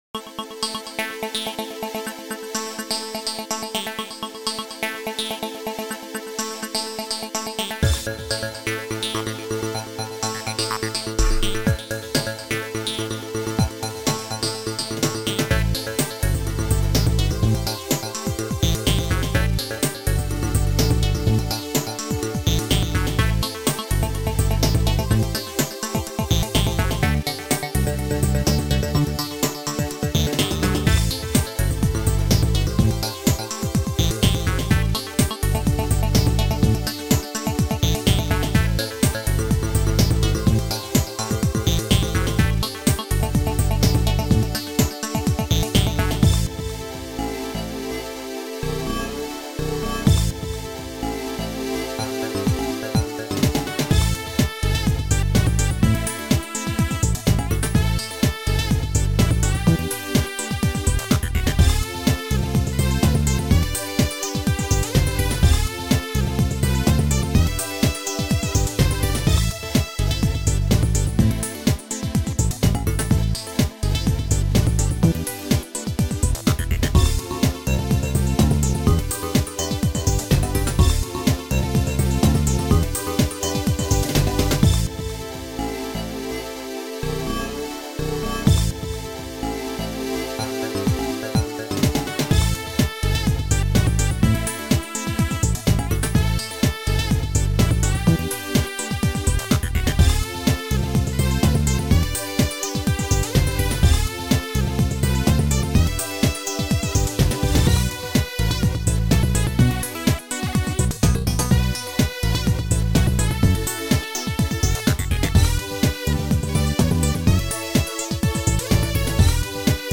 Sound Format: Noisetracker/Protracker
Sound Style: Disco